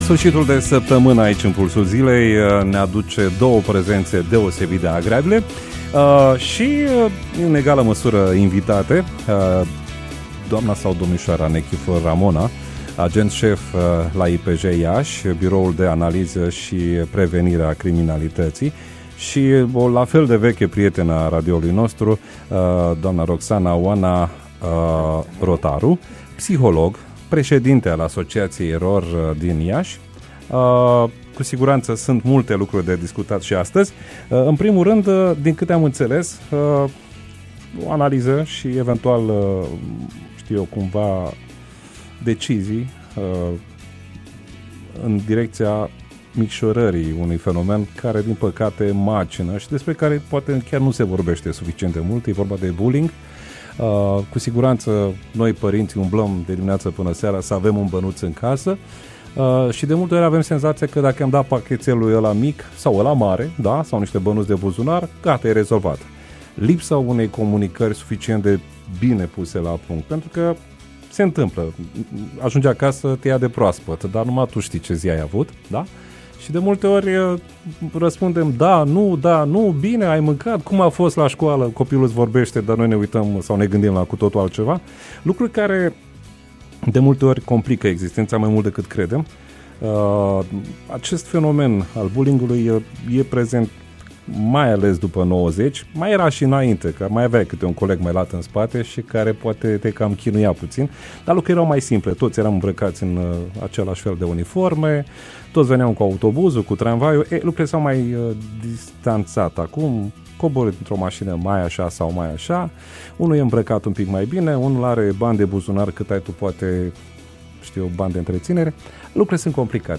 Interviu-Impact-Stop-Bullying.mp3